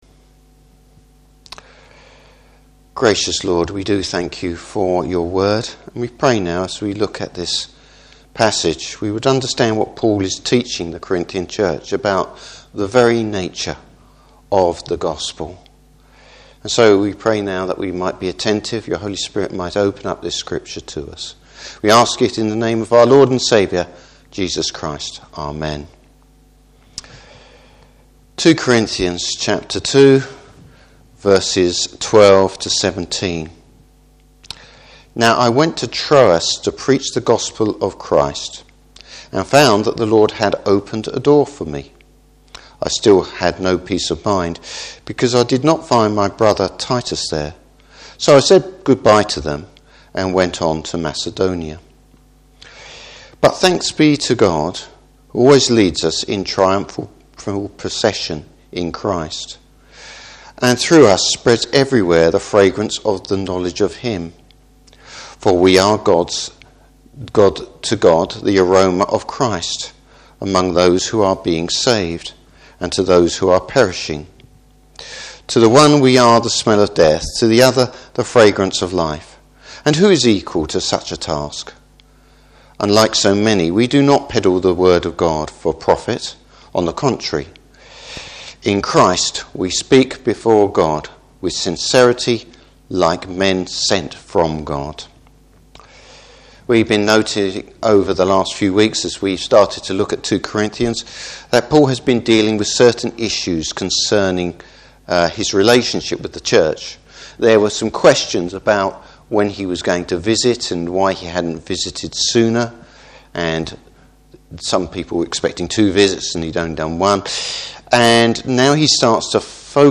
Service Type: Morning Service What is it that distinguishes the Gospel from other religious belief?